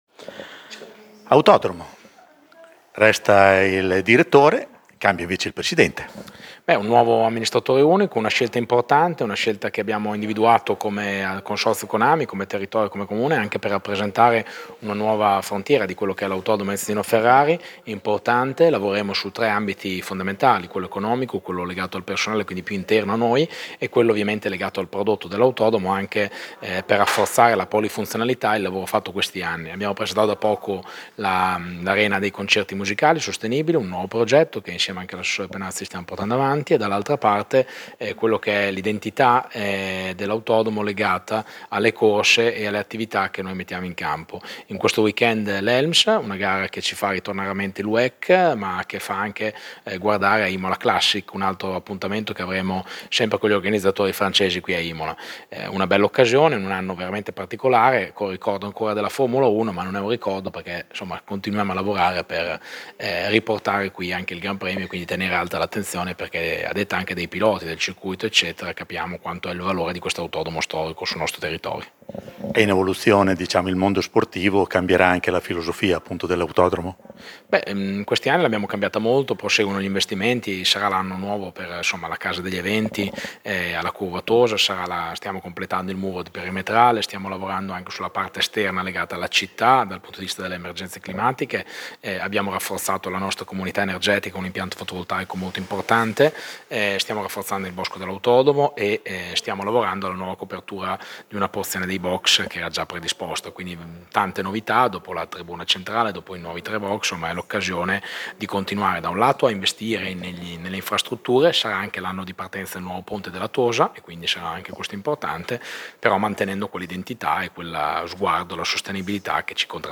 Marco Panieri, sindaco di Imola